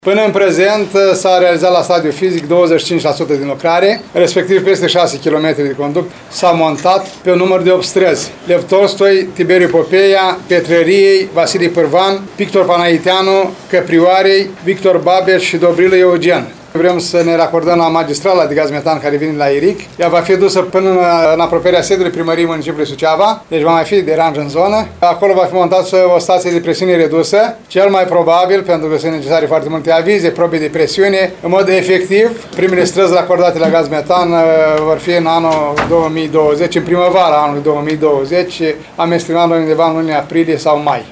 Primarul ION LUNGU a declarat că atunci se vor încheia lucrările executate de firma Test Prima și le-a recomandat localnicilor să-și cumpere lemne pentru a se încălzi la iarnă.